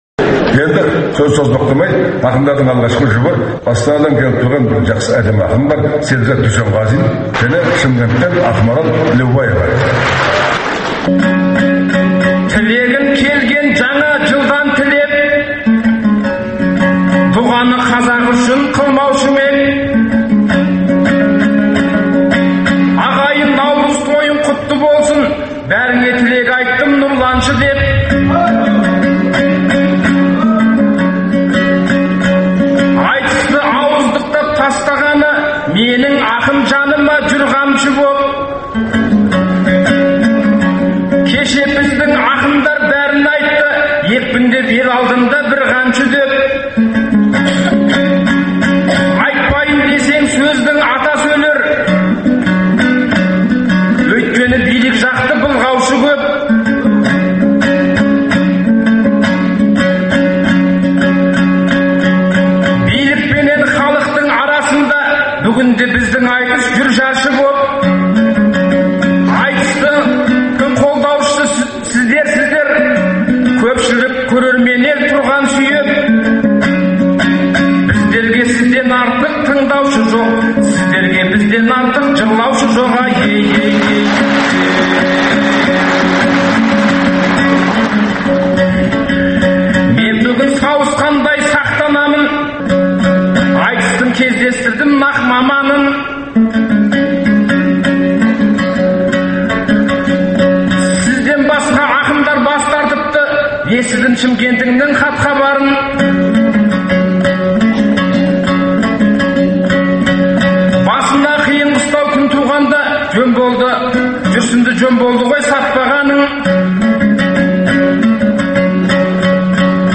Айтыстан үзінділер беріледі, ақындар айтысының үздік нұсқалары тыңдарменға сол қалпында ұсынылып отырады.